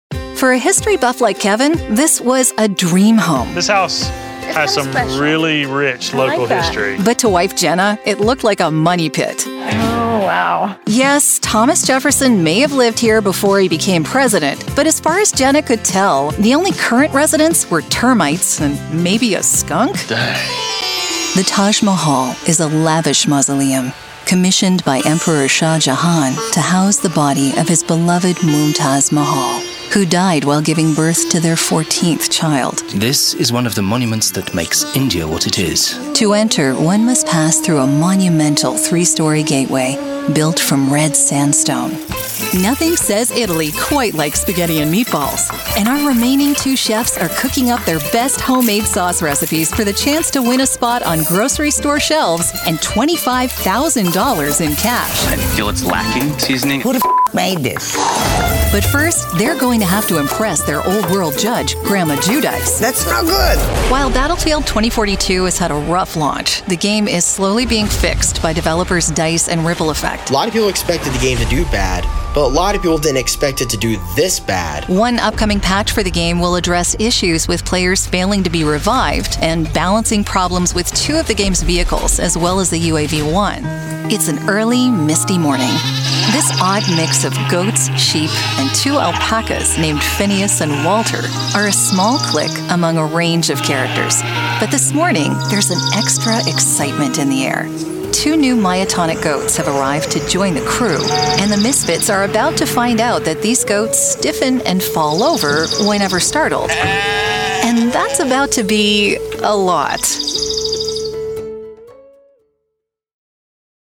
Voiceover : Narration : Women
Narration Demo